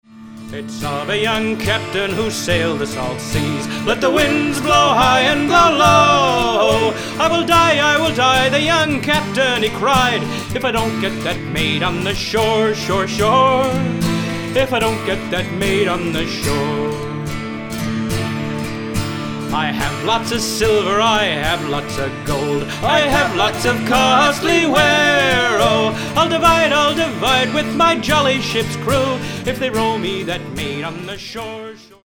- medium fast waltz